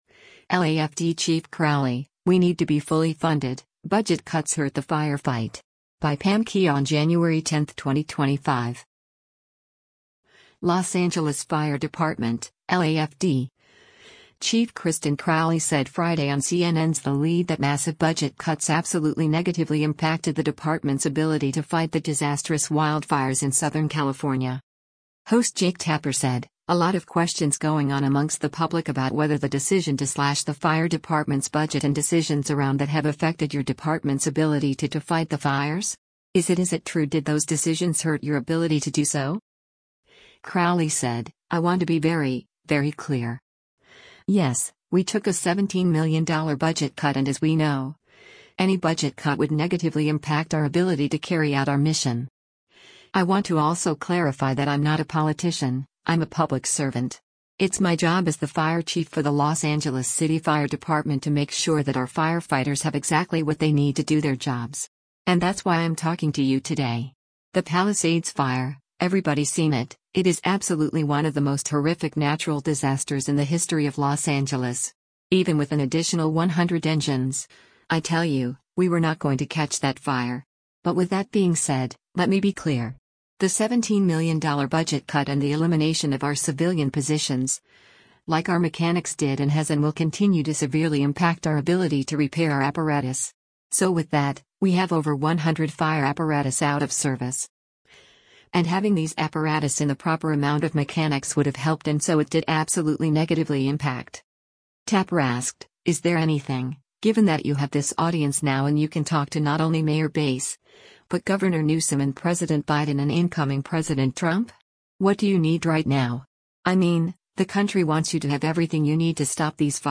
Los Angeles Fire Department (LAFD) chief Kristin Crowley said Friday on CNN’s “The Lead” that massive budget cuts “absolutely negatively impacted ” the department’s ability to fight the disastrous wildfires in Southern California.